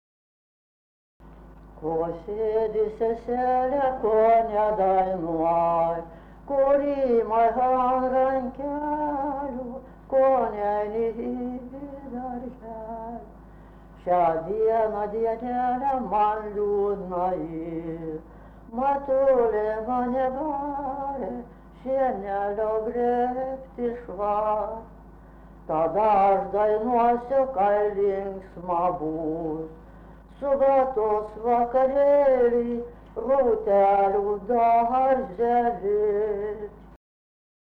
vokalinis